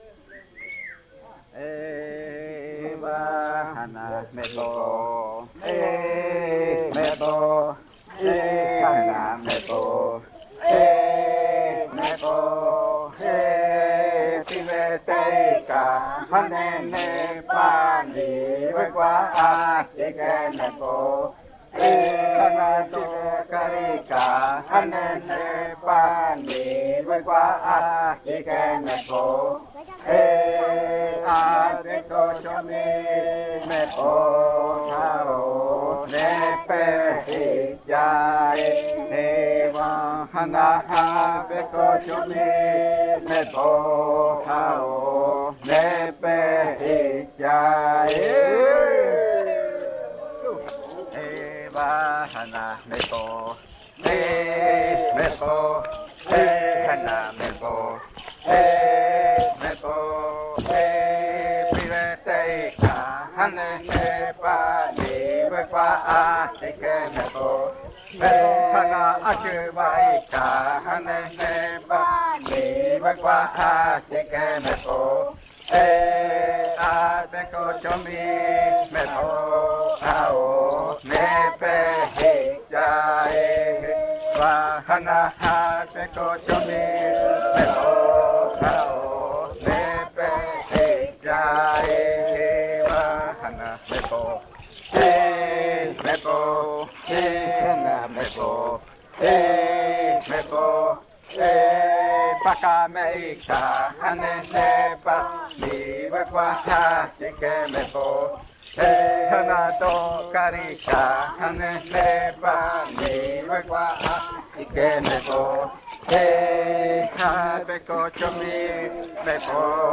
45.Baile de nombramiento. Canto n°3
Puerto Remanso del Tigre, departamento de Amazonas, Colombia